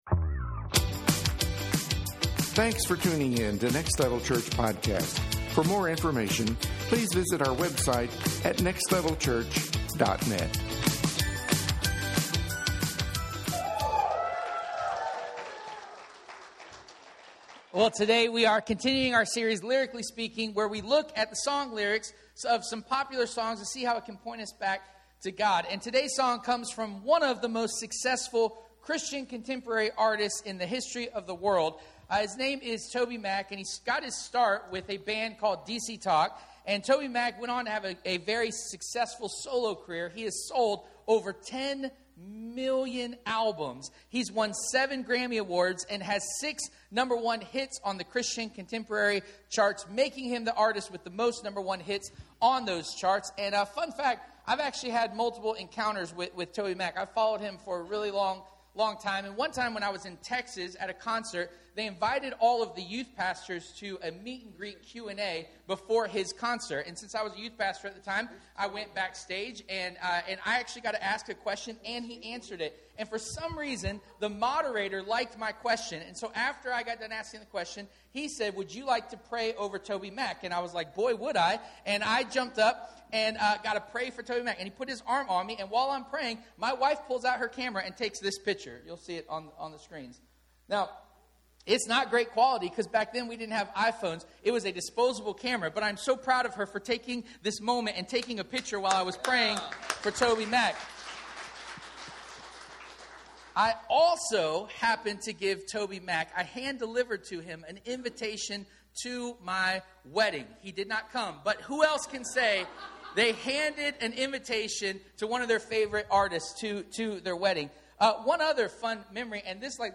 Lyrically Speaking 2022 Service Type: Sunday Morning « Lyrically Speaking 2022